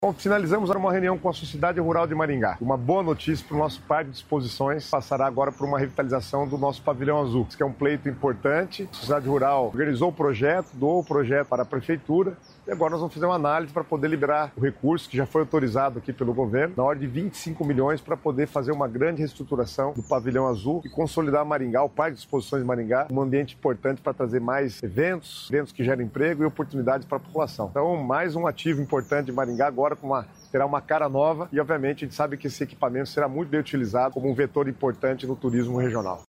Sonora do secretário das Cidades, Guto Silva, sobre a revitalização do pavilhão no Parque de Exposições de Maringá